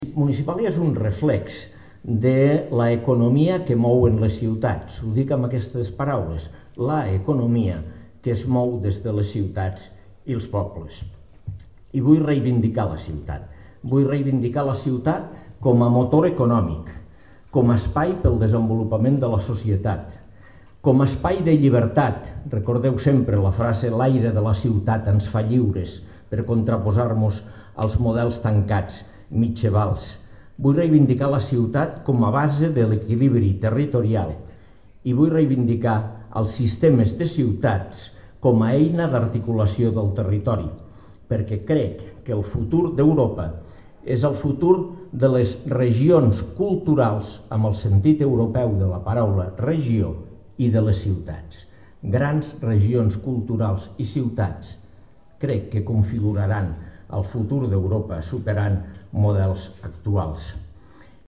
- El paer en cap ha reivindicat en la inauguració de Municipàlia el paper de les ciutats i pobles en l’agenda política i que es reconegui el rol del municipalisme en la construcció nacional - Joana Ortega ha inaugurat Saló Internacional d’Equipaments i Serveis Municipals
tall-de-veu-de-lalcalde-de-lleida-angel-ros-en-la-inauguracio-de-municipalia